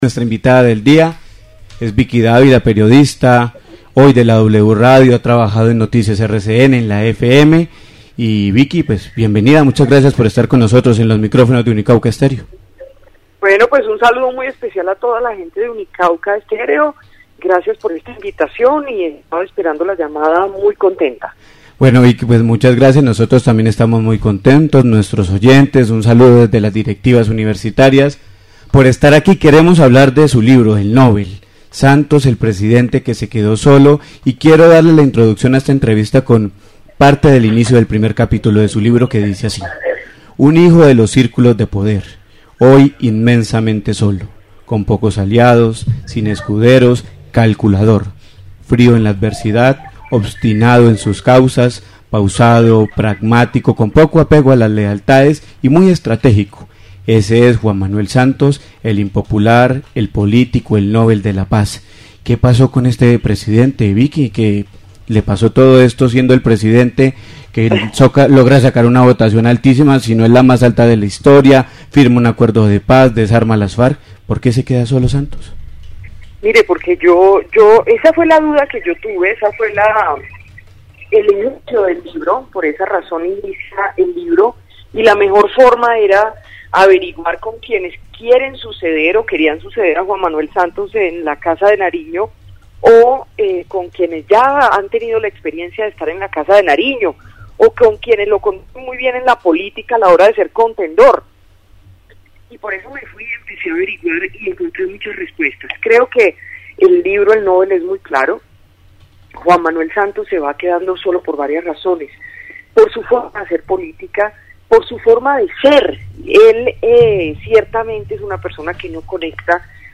Entrevista con Vicky Dávila
Vicky Dávila - Periodista.mp3